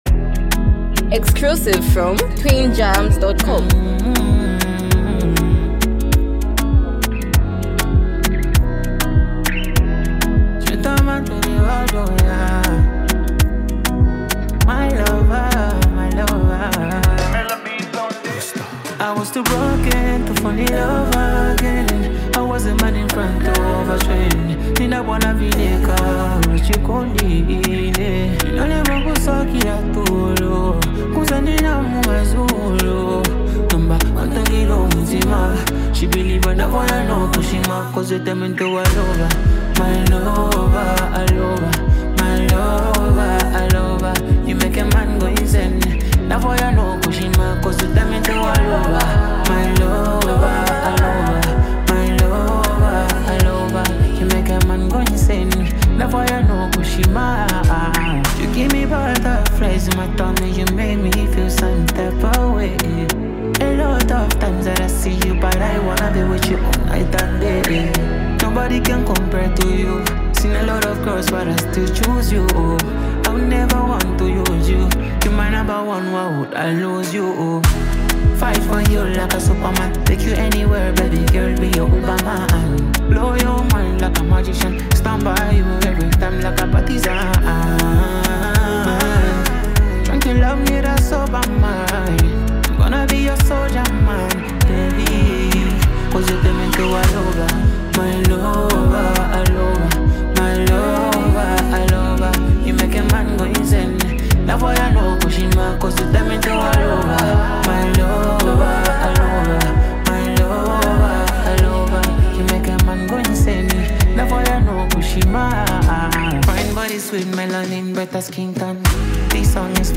heartfelt love song
with a catchy and melodic delivery